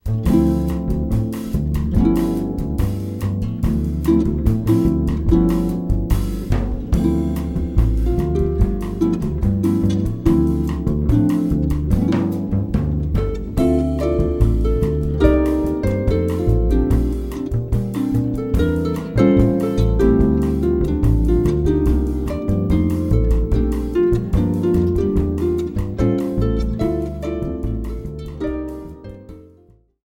harp